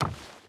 Footsteps / Wood / Wood Run 5.wav
Wood Run 5.wav